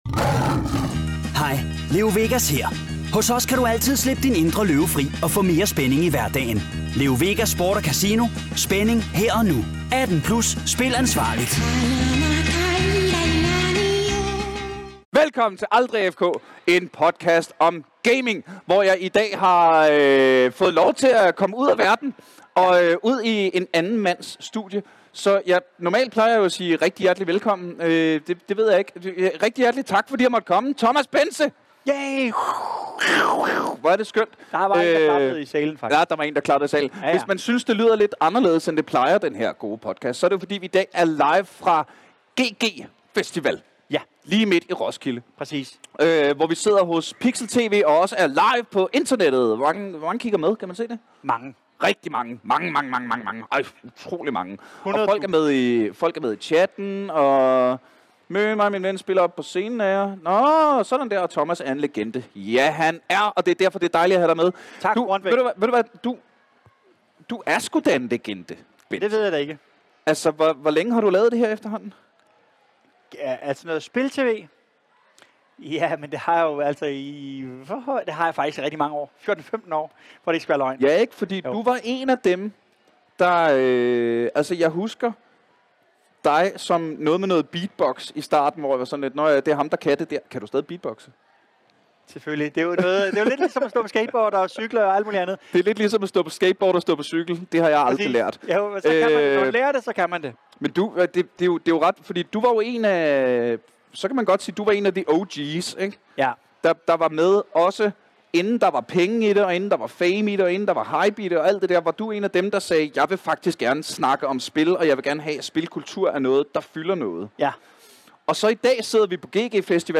Super rodet og super hyggeren afsnit optaget live på GG Festival.